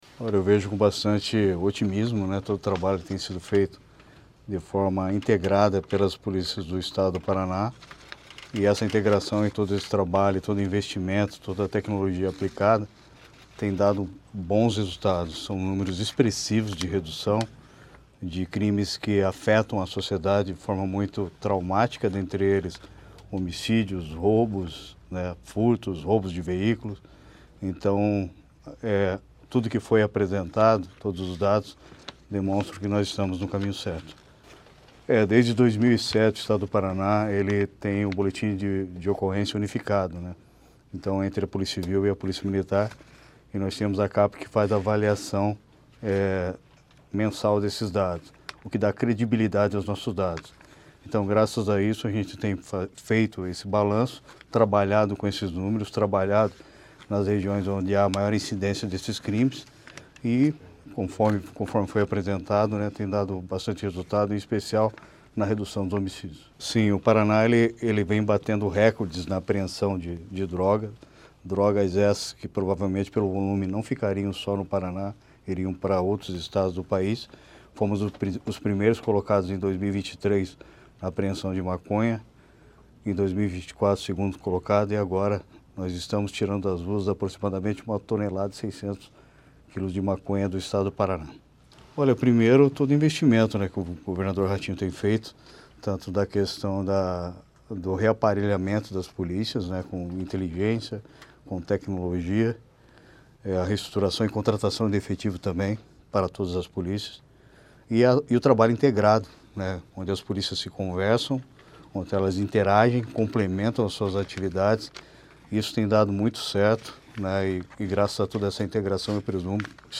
Sonora do secretário da Segurança Pública, Hudson Leôncio Teixeira, sobre os índices positivos no Estado